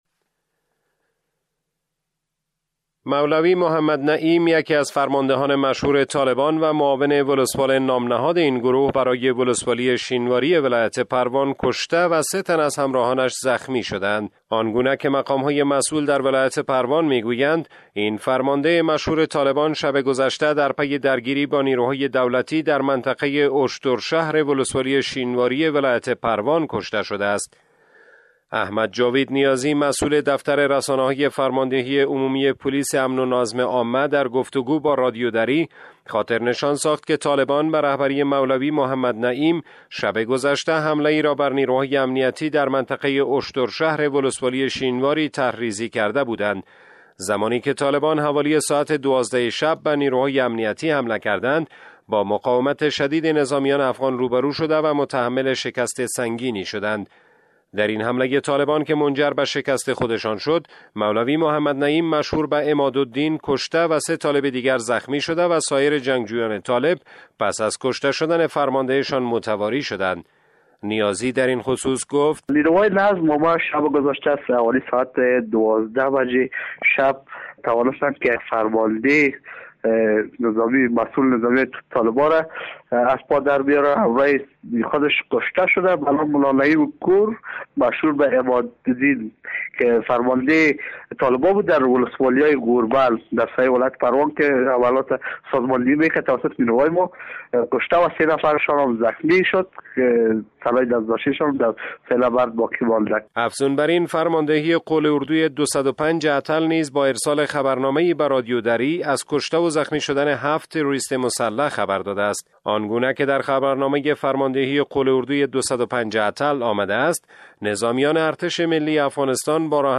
گزارش؛ هلاکت فرمانده مشهور طالبان در ولایت پروان